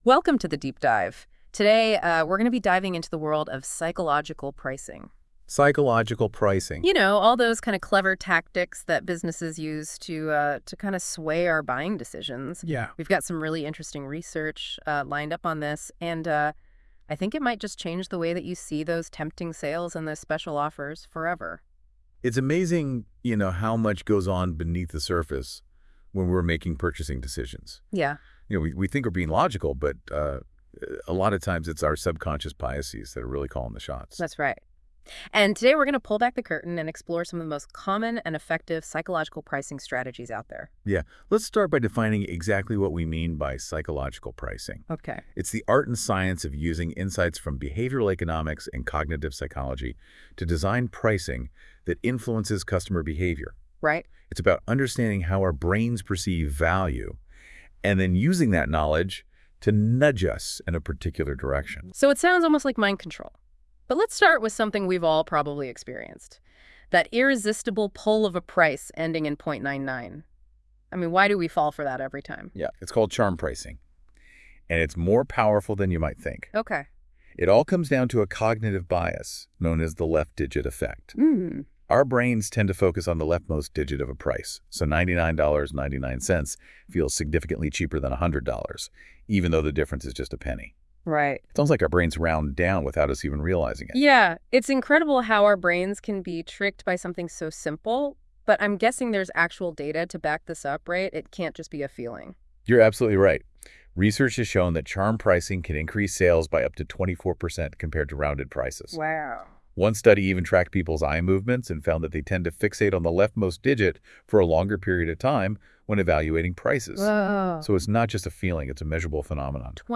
In this Deep DIVE: Your friendly AI hosts talk about how psychological pricing techniques and AI tools can help small business owners understand customer behavior, optimize pricing strategies. boost sales and profits